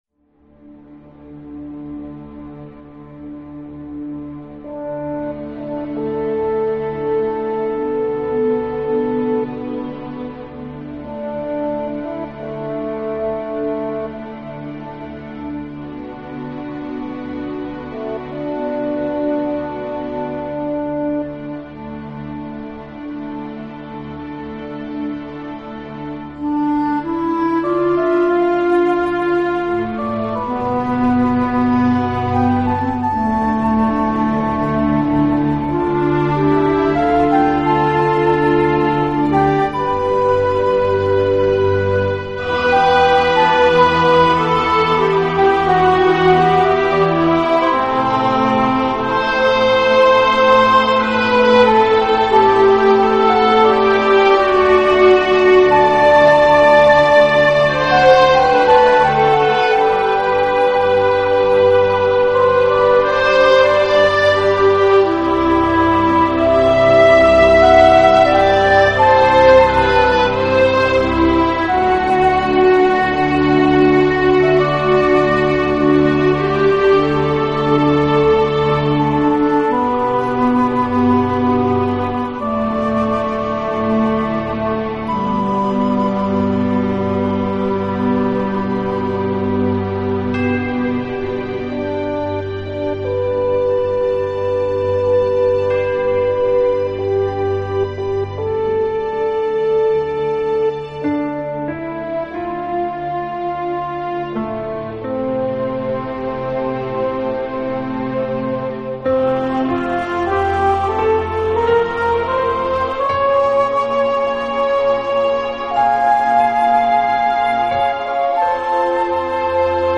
【新世纪音乐】
音乐类型：New Age/Ambient/Electronica
和整体相对"happy"的曲调。